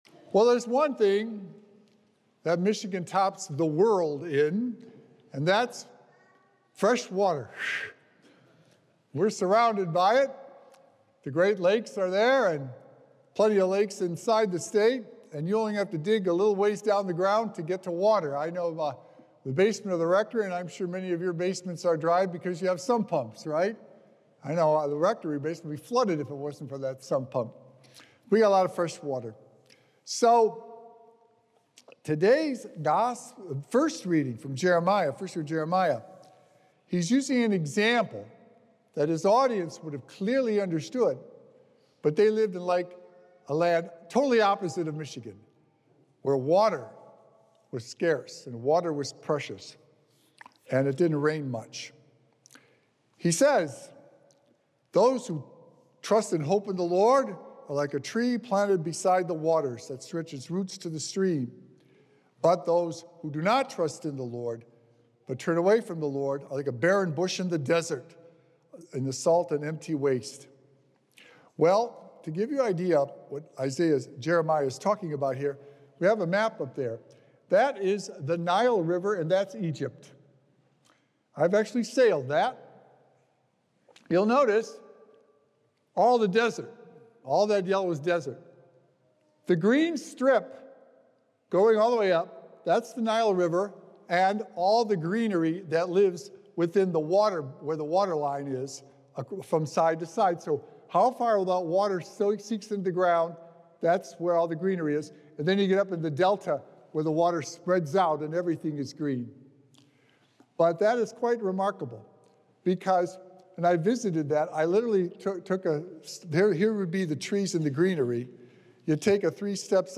Recorded Live on Sunday, February 16th, 2025 at St. Malachy Catholic Church.
Weekly Homilies